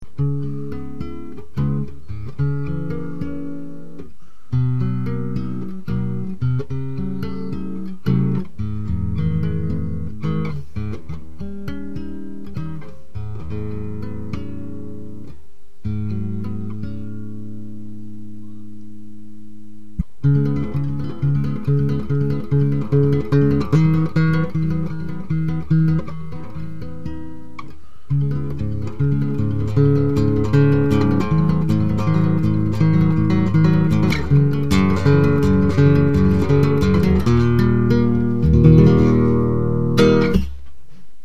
As promised here is a recording of the classical guitar. This was recorded direct into a computer microphone so the quality of the audio is not great but the guitar sounds pretty nice.